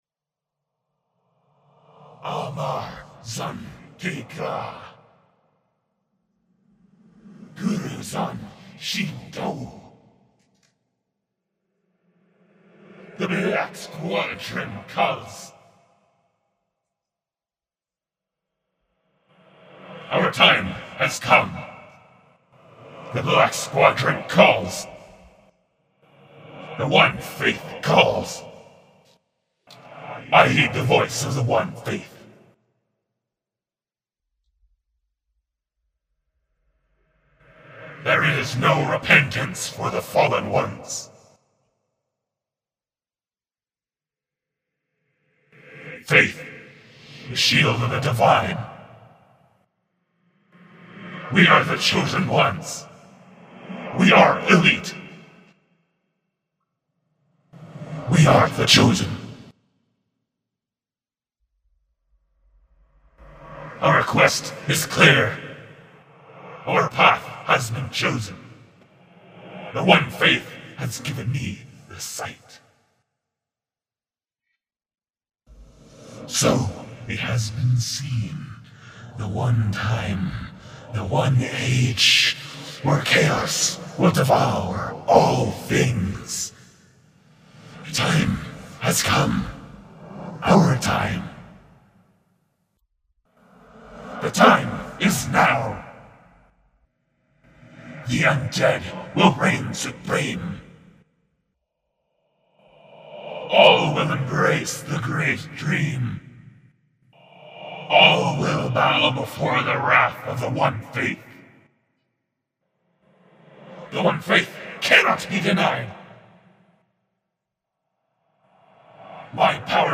The voice pieces are all my own voice and sound editing.
undeadeliteresponsesfull.mp3